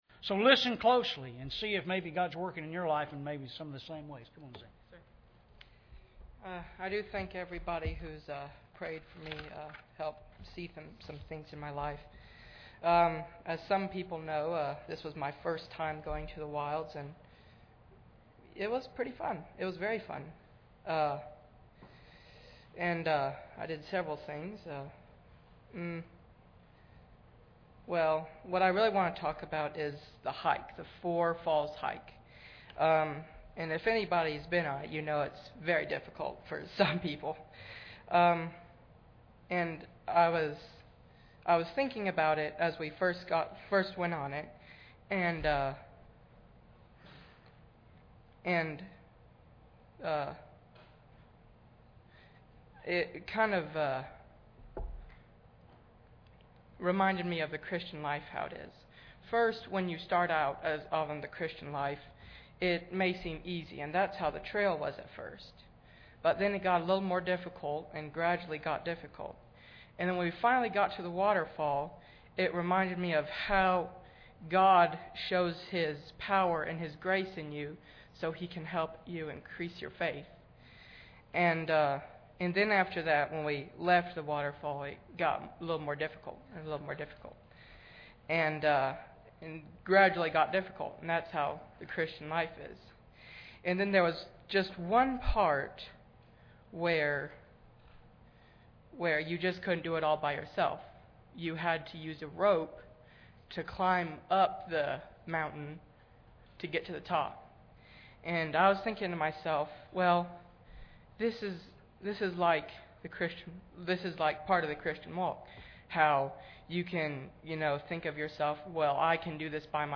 Members of the church who spent time at the Wilds weekend retreat share testimonies of how God worked in their lives.